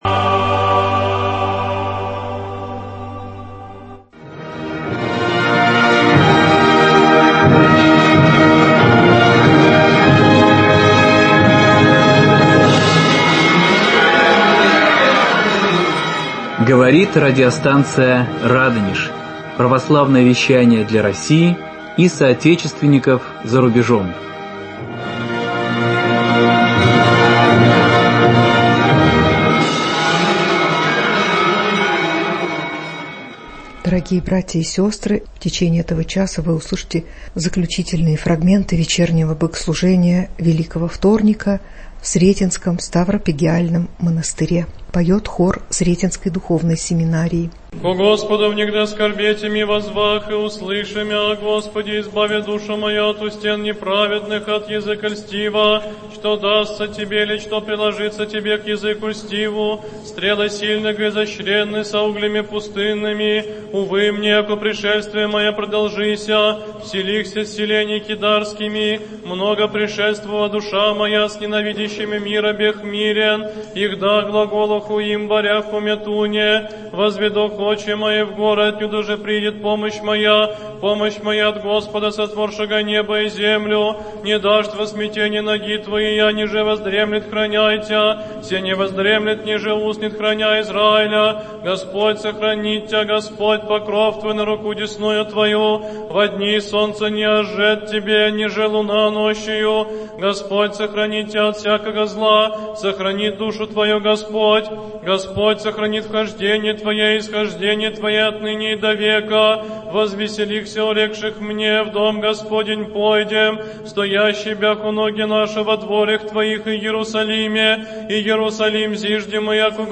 Фрагменты богослужения в Великий вторник в Сретенском монастыре ч.3 Хор Сретенской семинарии